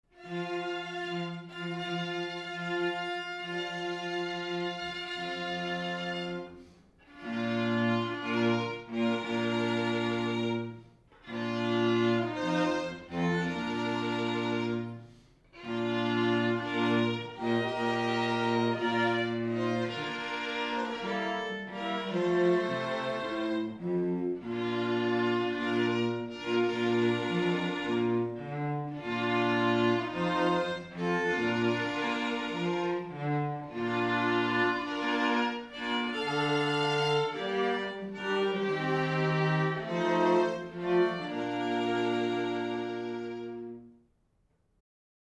We have made a home recording of some snippets of the most popular Processionals and Recessionals as an audio aid in making your selections.